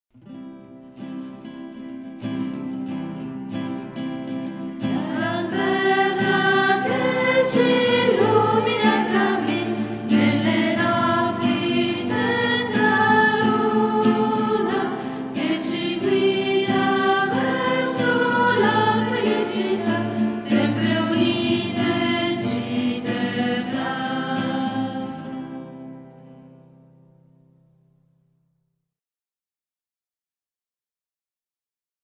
Canto stupendo!